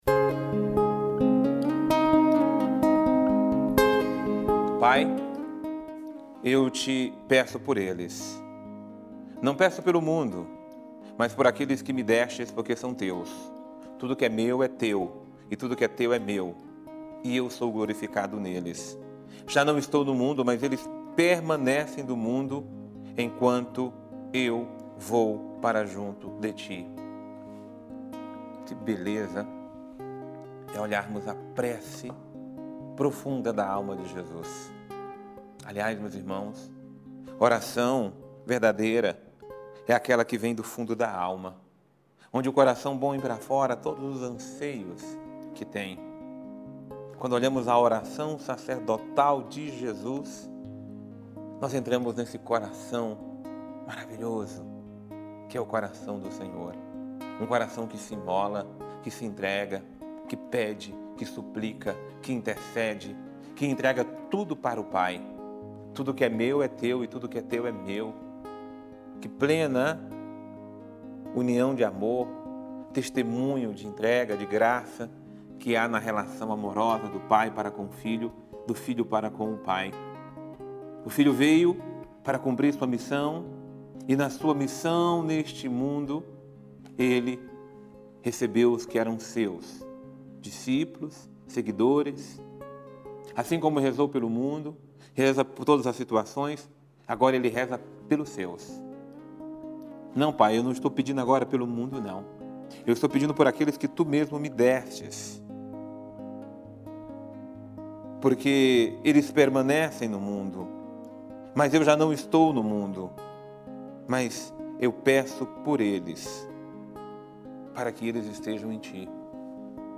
Homilia diária | Unamos a nossa oração ao coração de Jesus